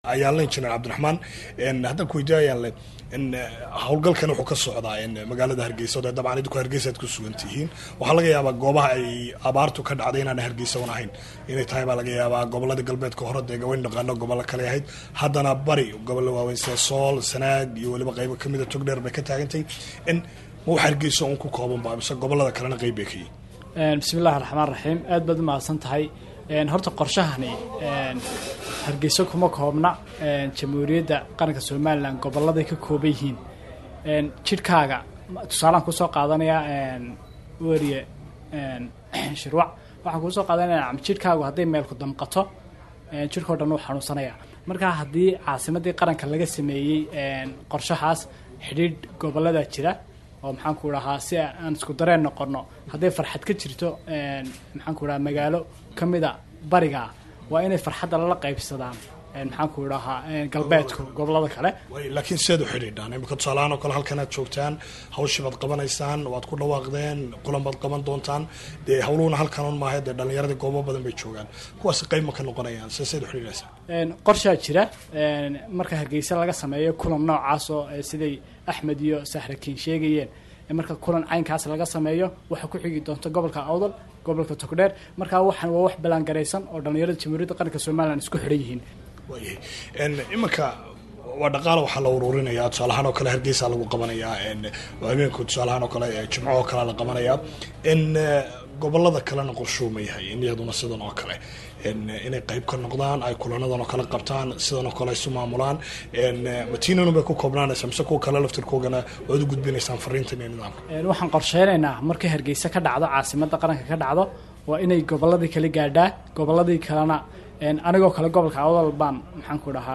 Warbixin: Abaaraha Somaliland